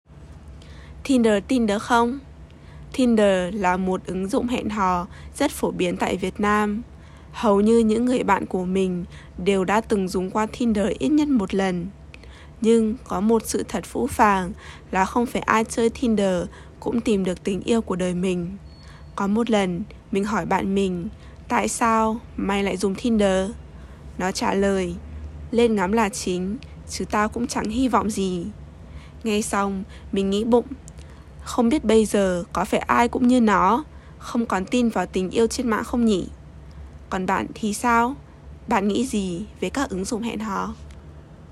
下方有短文的中文翻譯、越南話音檔（河內腔），大家可以做聽力的練習。
* 越南話音檔 （河內腔）：